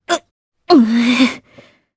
Priscilla-Vox_Sad_jp.wav